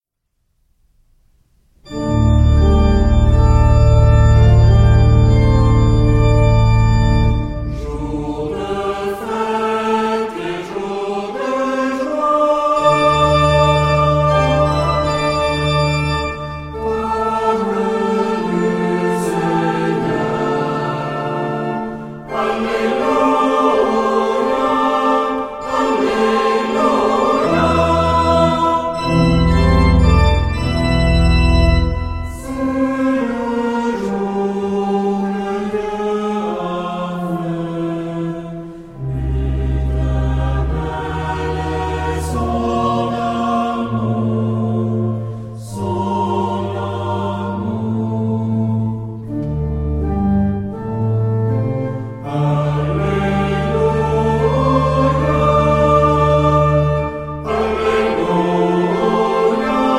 Genre-Style-Form: Hymn (sacred)
Mood of the piece: joyous
Type of Choir: SAH  (3 mixed voices )
Instrumentation: Organ  (1 instrumental part(s))
Tonality: A tonal center